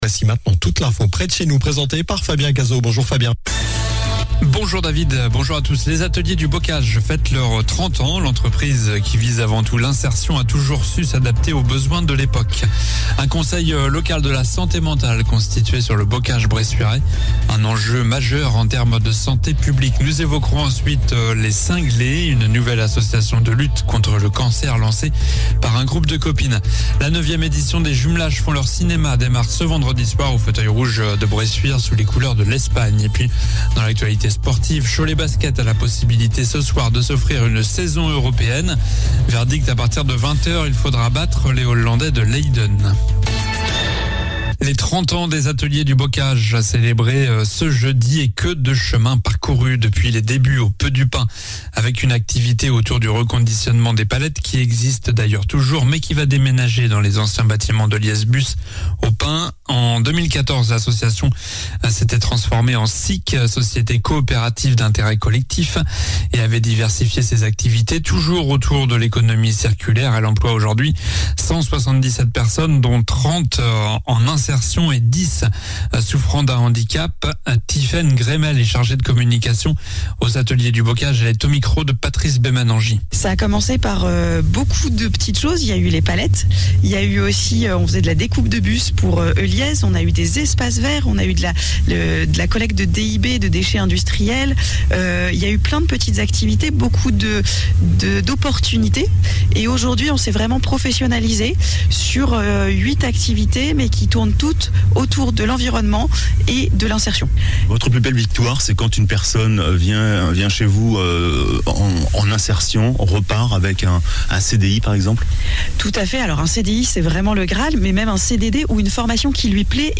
Journal du vendredi 30 septembre (midi)